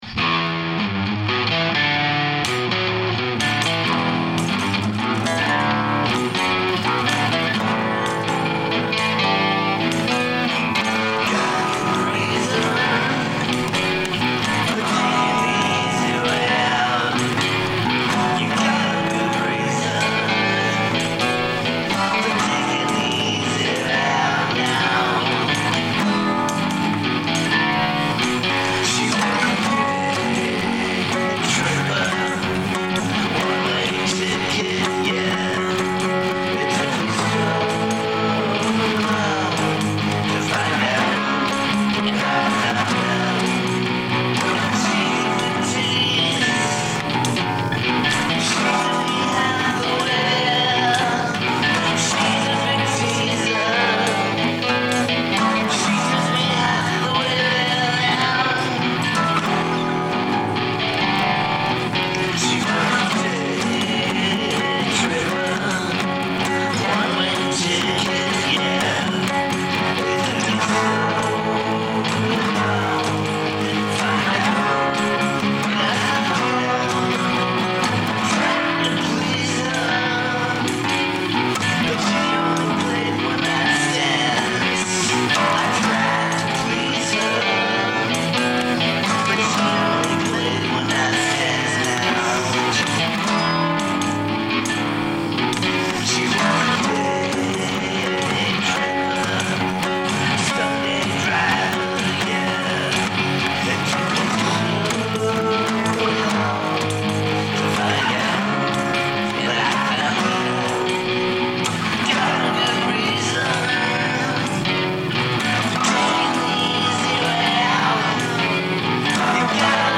Click on this listen to a punk rock version.